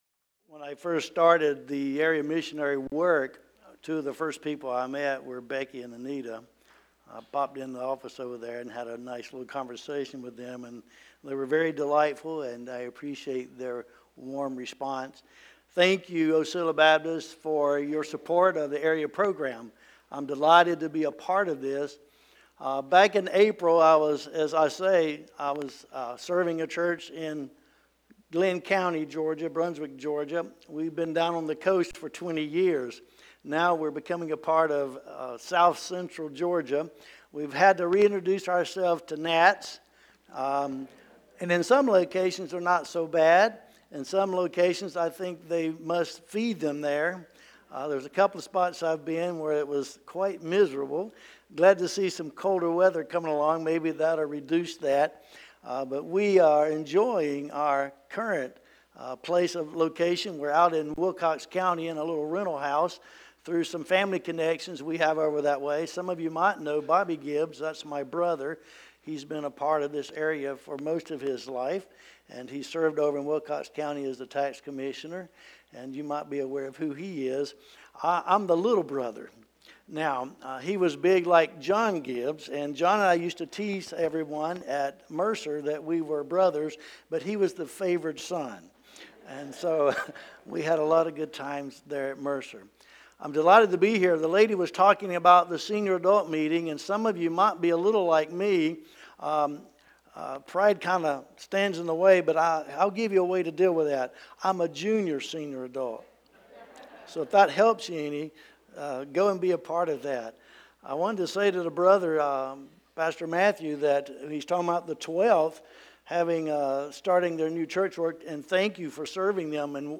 Sermons - OCILLA BAPTIST CHURCH